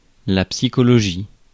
Ääntäminen
Ääntäminen Tuntematon aksentti: IPA: /psi.kɔ.lɔ.ʒi/ Haettu sana löytyi näillä lähdekielillä: ranska Käännös Substantiivit 1. psicología {f} Suku: f .